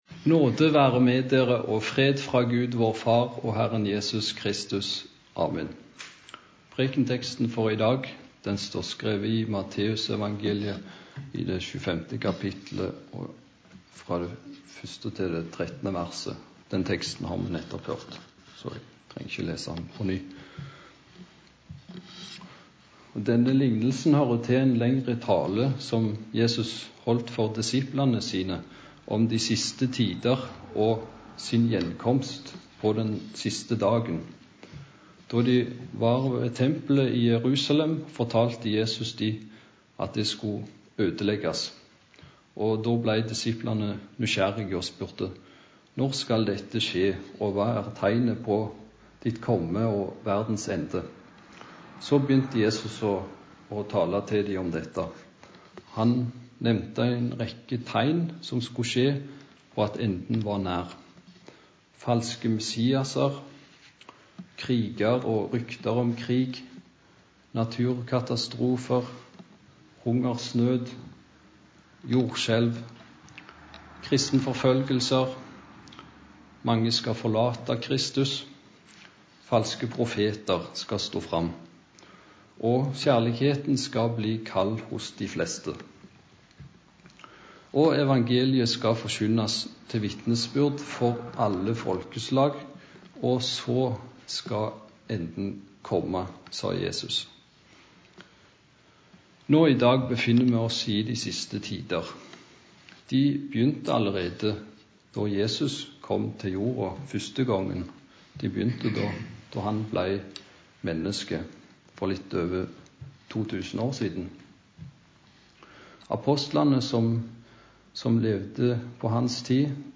Preken på søndag før Domssøndag